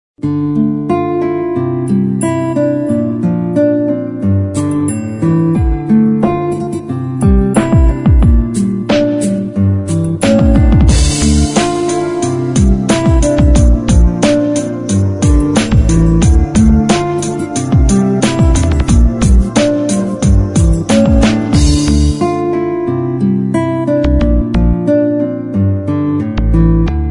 • Качество: 48, Stereo
без слов
инструментальные